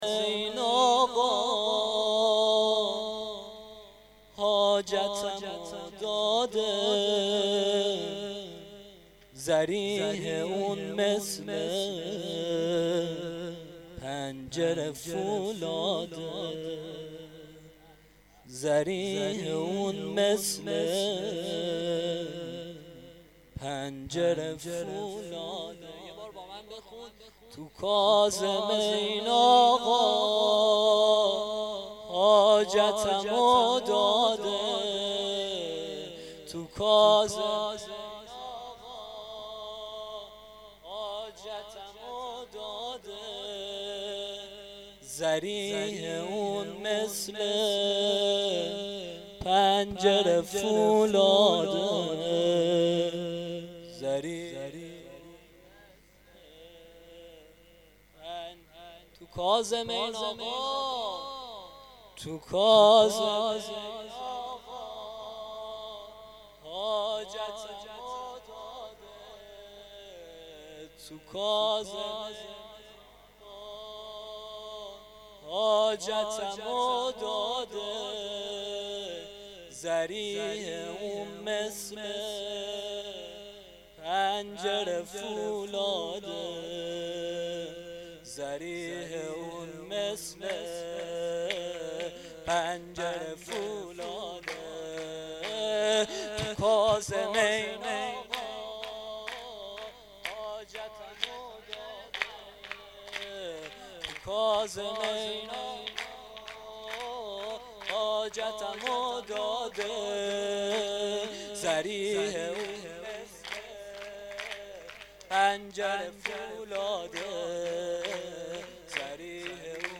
سرودامام-جواد-ع-19.2.mp3